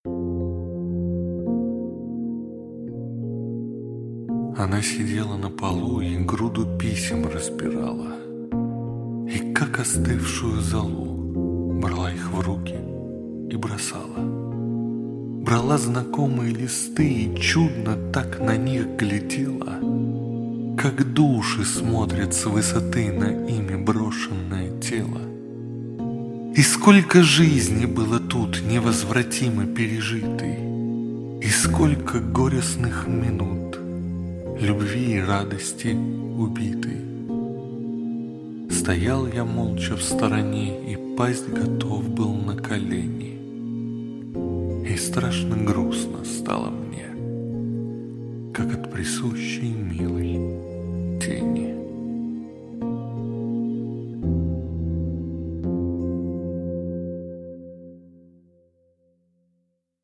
Аудиокнига Шедевры поэзии. От Пушкина до Евтушенко. Часть первая | Библиотека аудиокниг